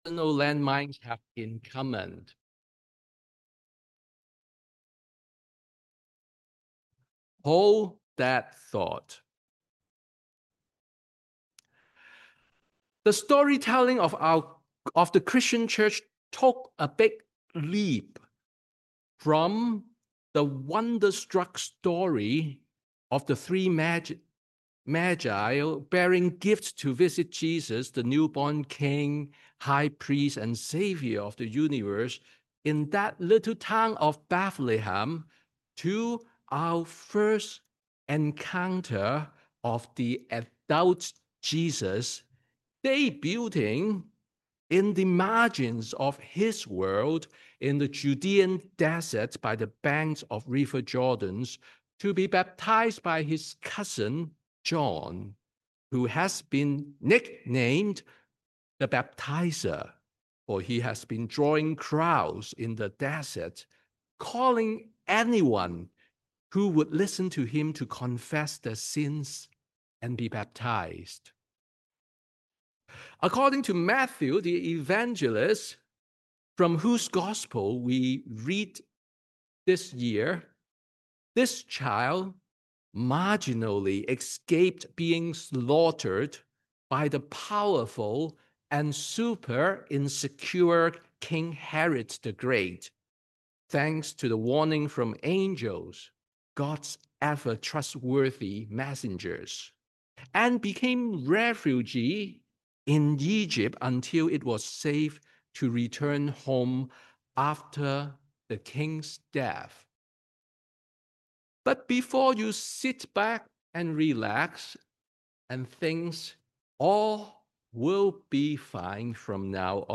Sermon on the Baptism of the Lord